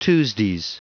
Prononciation du mot tuesdays en anglais (fichier audio)
Prononciation du mot : tuesdays